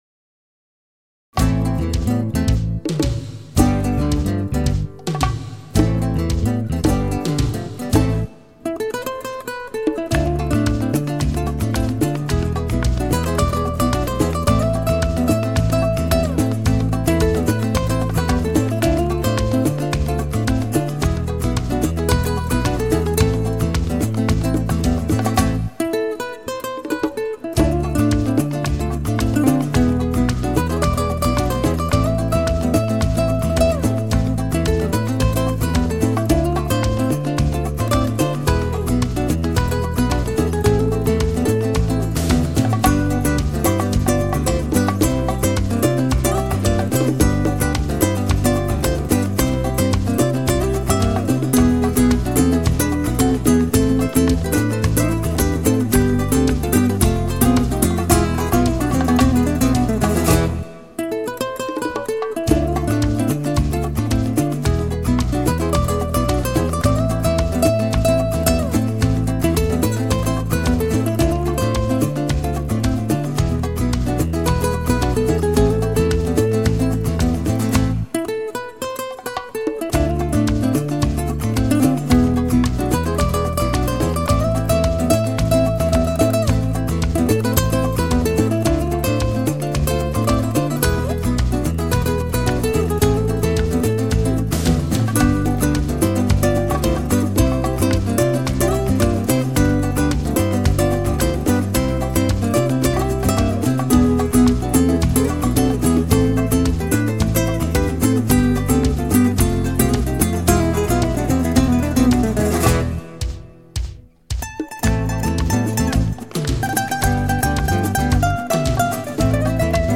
Latin Guitar
Acoustic Guitar